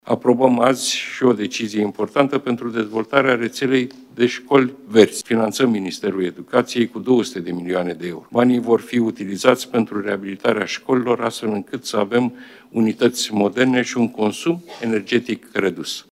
„Aprobăm azi și o decizie importantă pentru dezvoltarea rețelei de școli verzi. Finanțăm Ministerul Educației cu 200 de milioane de euro. Banii vor fi utilizați pentru reabilitarea școlilor, astfel încât să avem unități moderne și un consum energetic redus”, a mai precizat prim-ministrul Marcel Ciolacu.